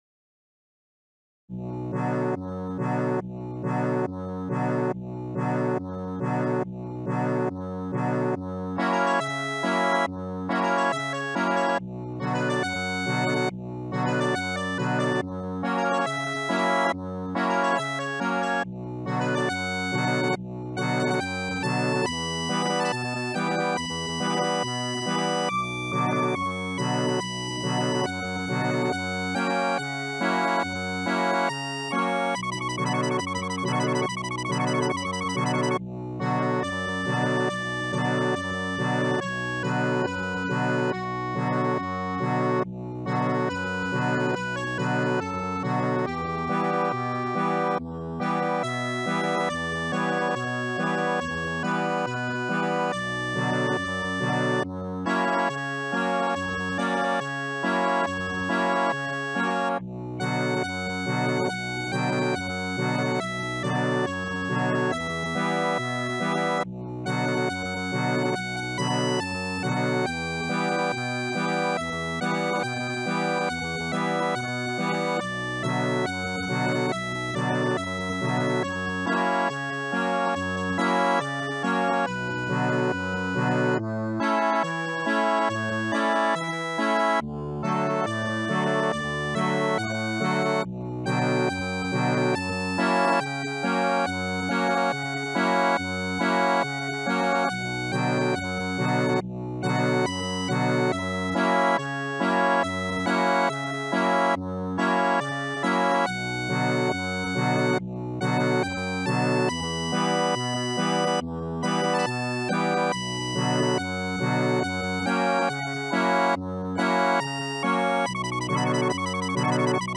Instrument : Accordéon